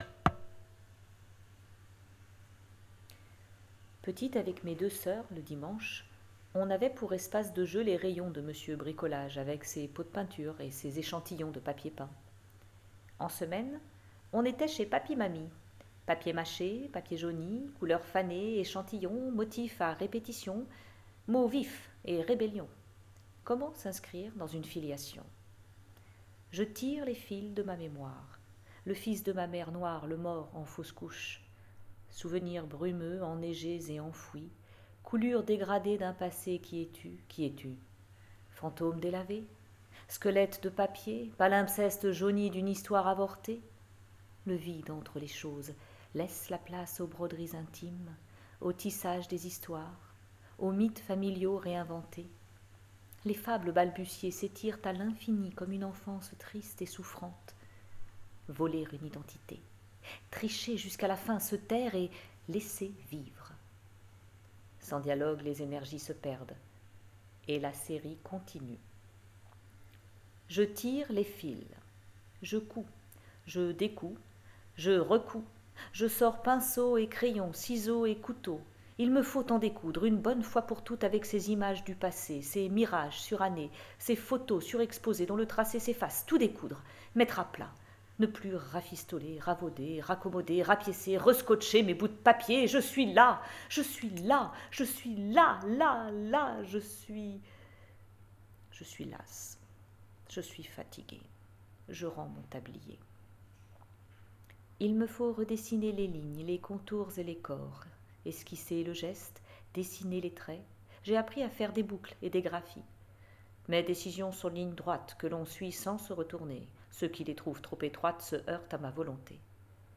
Même pas mortes (vernissage)